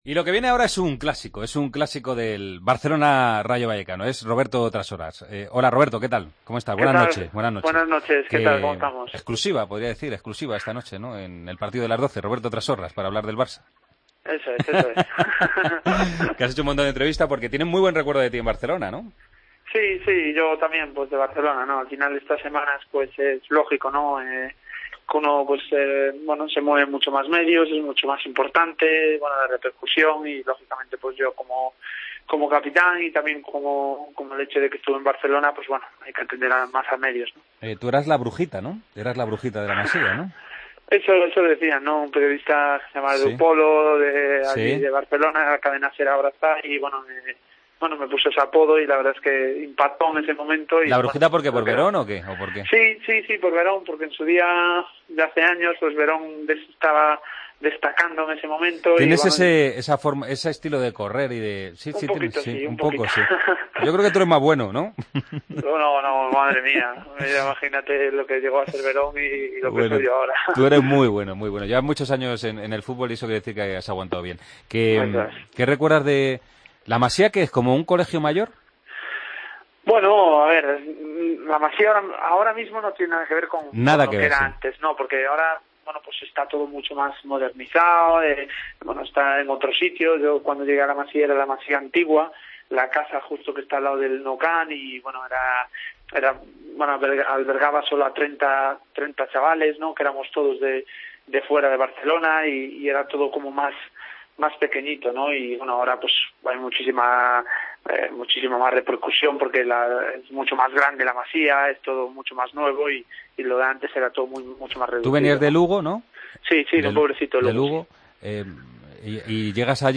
El jugador del Rayo Vallecano habló en los micrófonos de El Partido de las 12 sobre el encuentro que este sábado jugará el equipo vallecano en Barcelona: “Llegué al Barça con 14 años y recuerdo estar en la Masía con Puyol, Iniesta, Arteta...”.